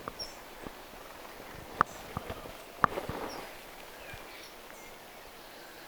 ilmeisestikin metsäkirvislintu
ilmeisestikin_metsakirvislintu.mp3